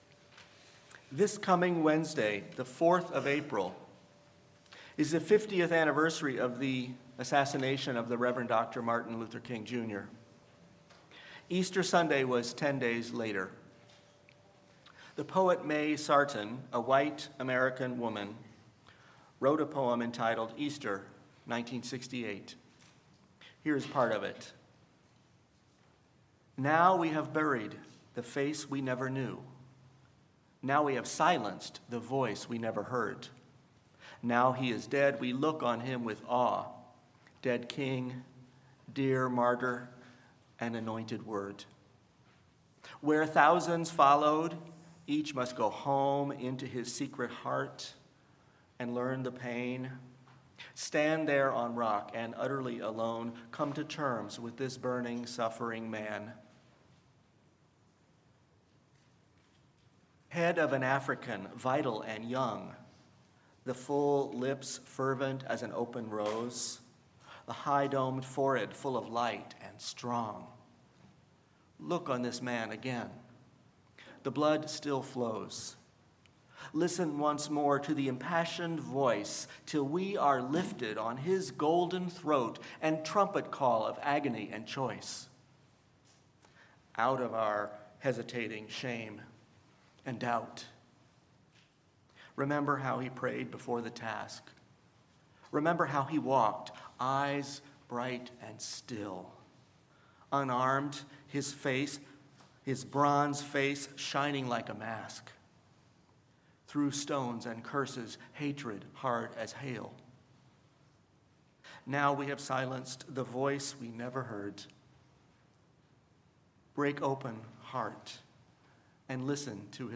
Sermon-Easter-Foolish-Hope.mp3